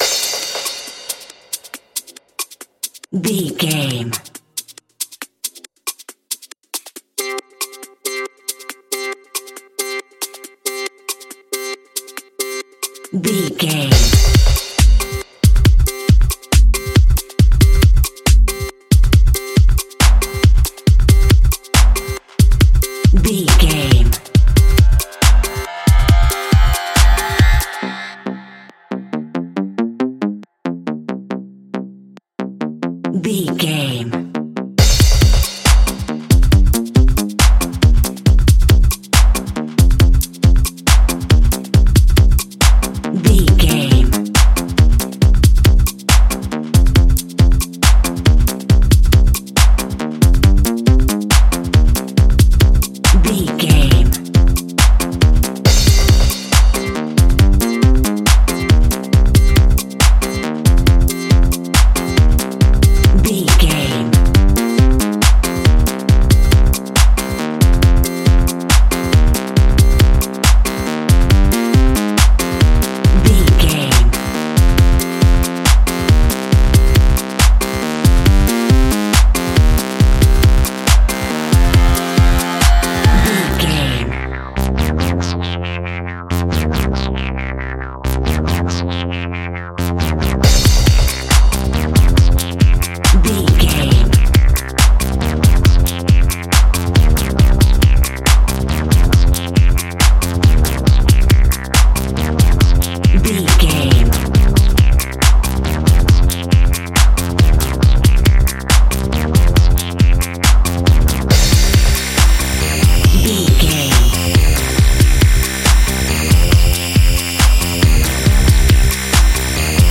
Future Dubstep Sounds.
Epic / Action
Fast paced
Diminished
aggressive
powerful
dark
groovy
futuristic
energetic
drum machine
synthesiser
breakbeat
synth leads
synth bass